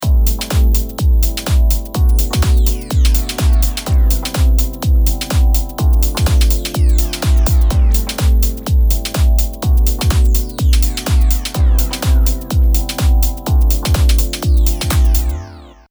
ブロック3とディレイのみの音で聴いてください。